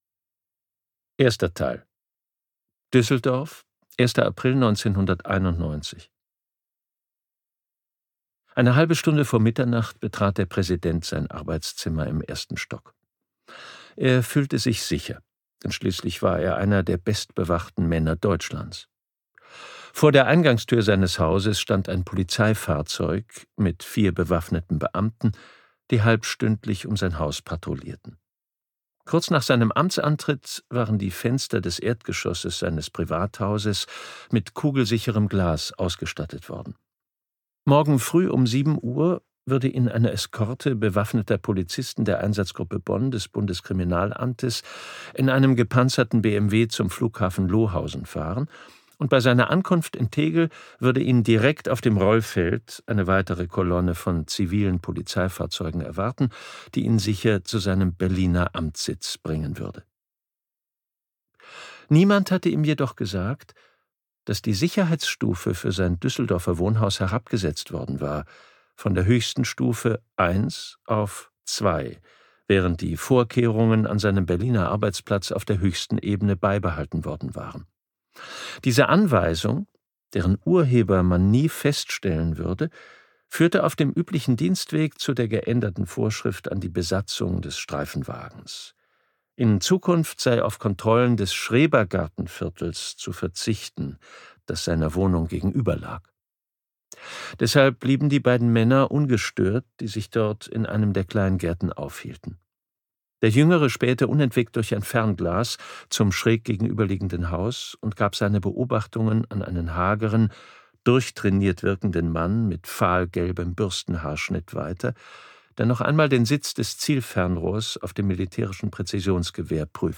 Die blaue Liste - Wolfgang Schorlau | argon hörbuch